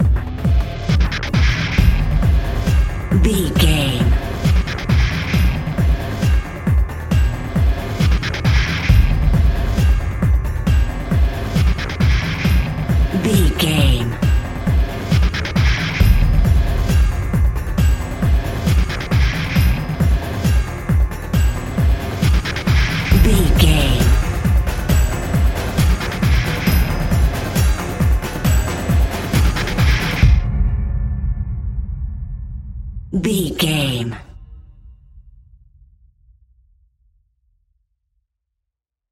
Aeolian/Minor
Fast
tension
ominous
dark
eerie
driving
synthesiser
drums
drum machine